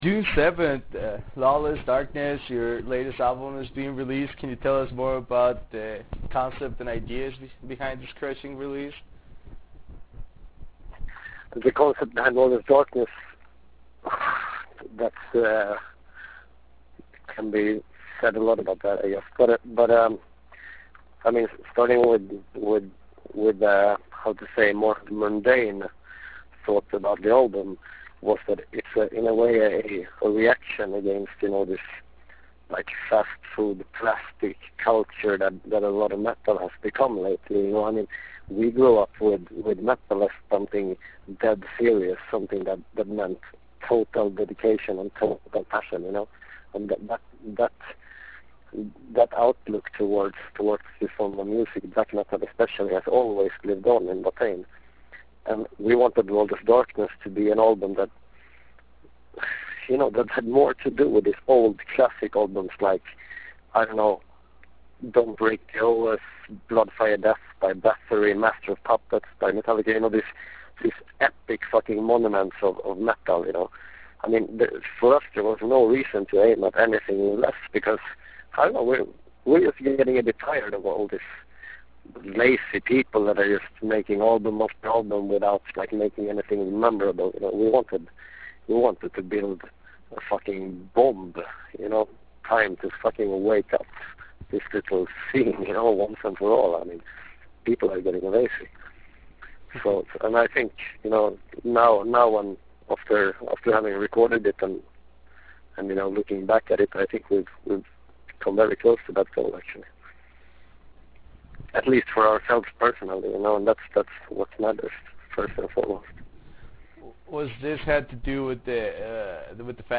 Interview with Watain - Erik Danielsson